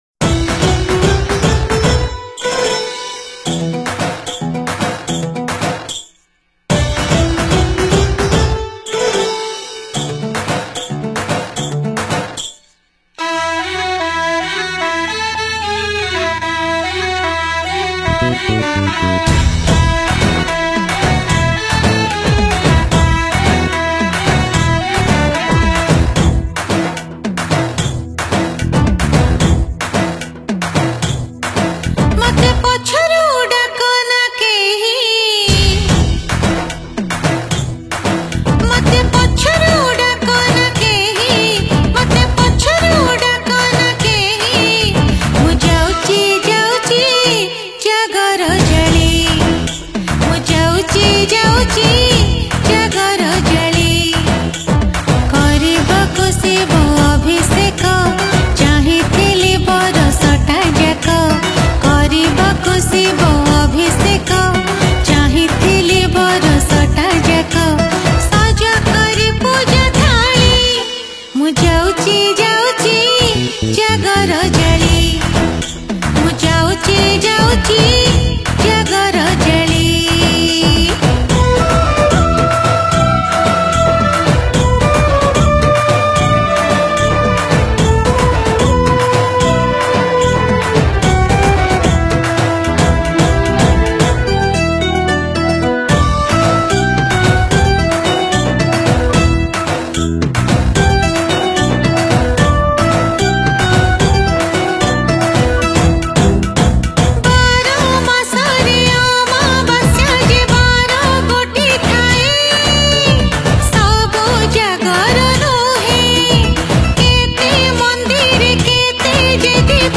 Jagara Special Odia Bhajan Song